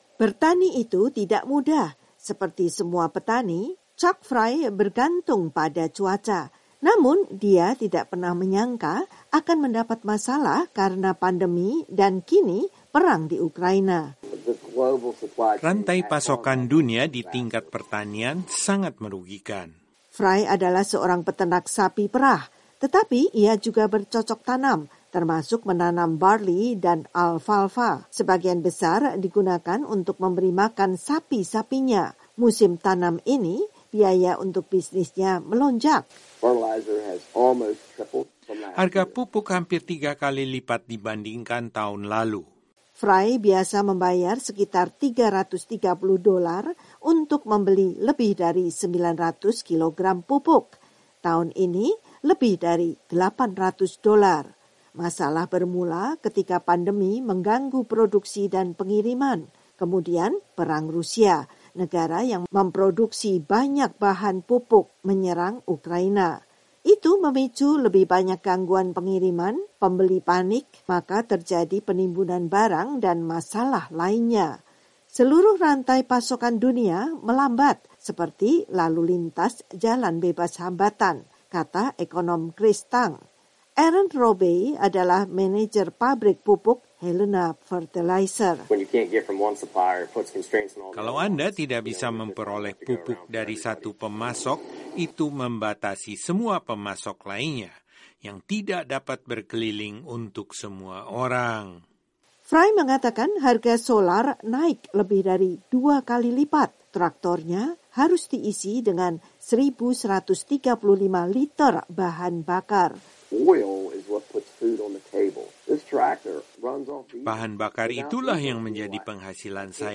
VOA meliput dari lahan peternakan Rocky Point di Frederick, Maryland.